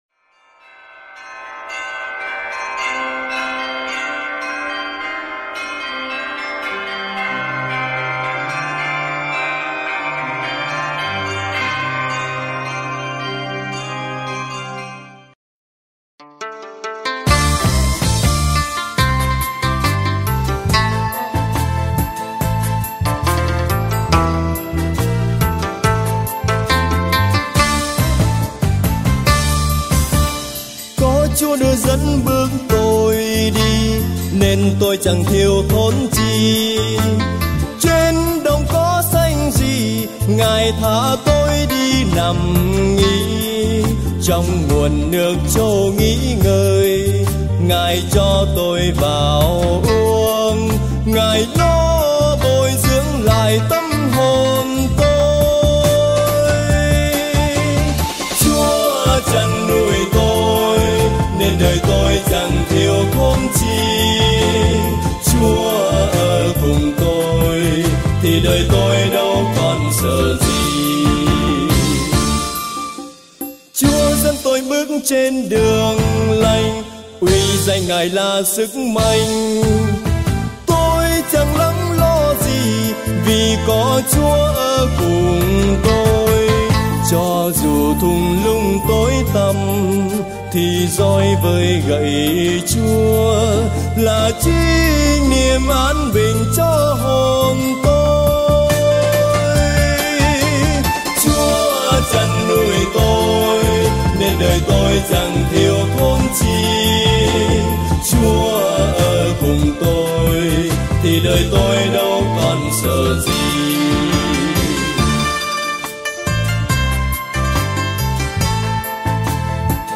Thể loại 🌾 Nhạc Thánh Ca, 🌾 Thánh Vịnh - Đáp Ca